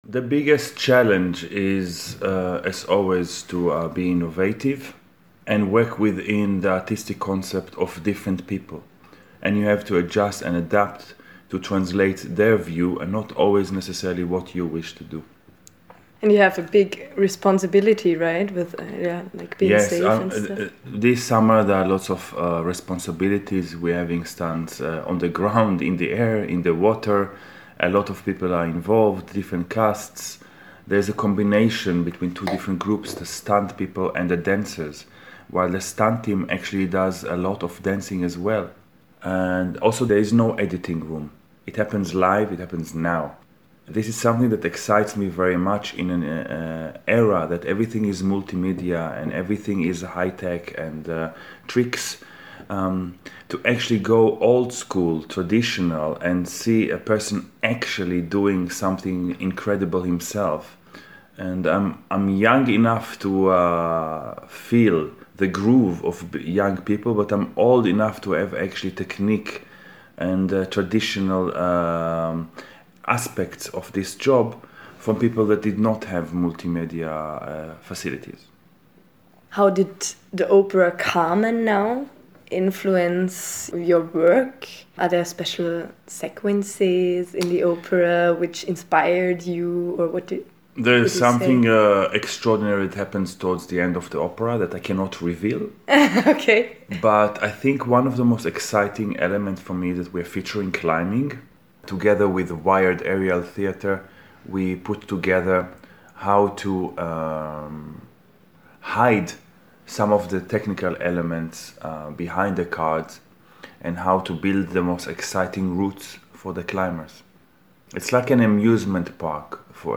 Interview für die Ohren: Wenn das Bühnenbild zum Stuntschauplatz wird